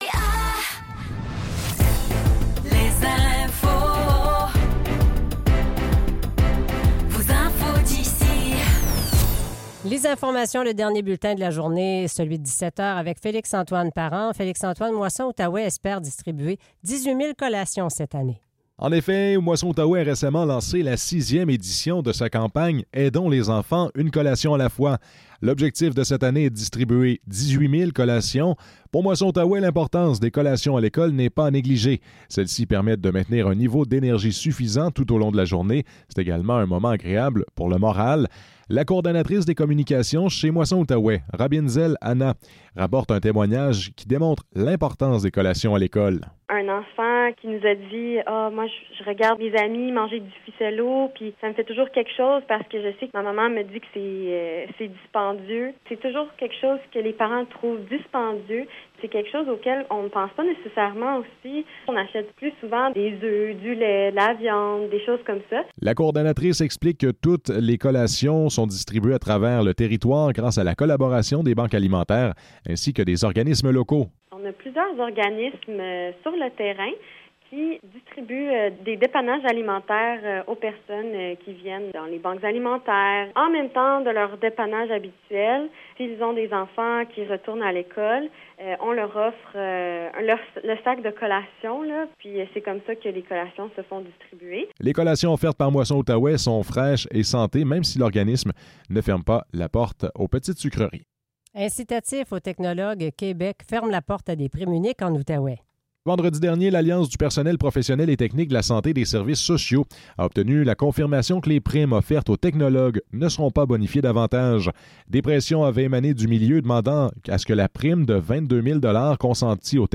Nouvelles locales - 4 septembre 2024 - 17 h